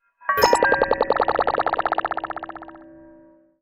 UI_SFX_Pack_61_34.wav